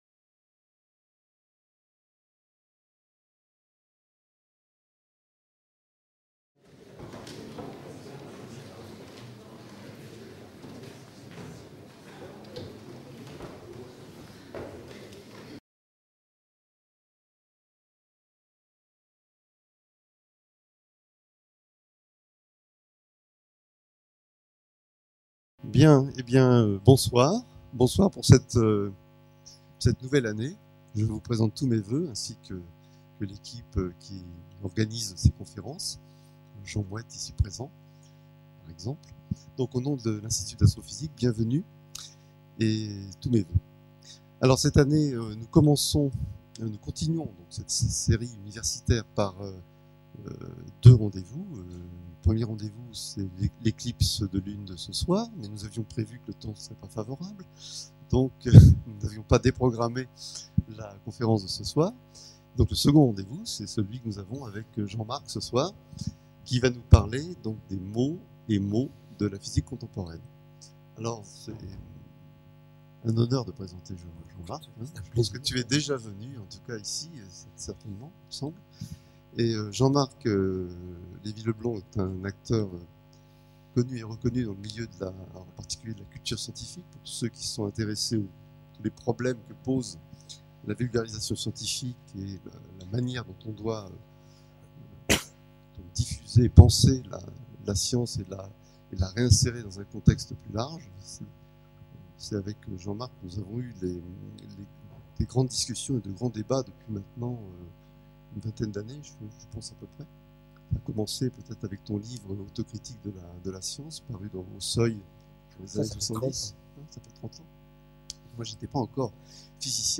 Conférence donnée par Jean-Marc Lévy-Leblond, dans le cadre des conférences publiques de l'IAP. La qualité de l'image et du son de cette captation vidéo n'est pas aussi bonne qu'elle pourrait l'être si elle était réalisée aujourd'hui, mais l'intérêt qu'elle présente pour l'histoire de la diffusion des connaissances mérite sa présentation ici, à titre d'archive.